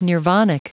Prononciation du mot : nirvanic
nirvanic.wav